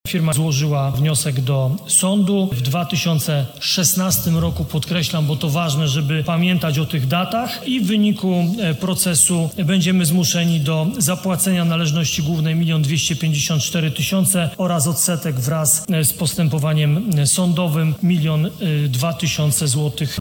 – Chodzi o zlecenie prac nad murem oporowym i dodatkowym oświetleniem chodnika, które później nie znalazły odzwierciedlenia w aneksach – mówił w trakcie sesji Rady Miasta prezydent Nowego Sącza Ludomir Handzel.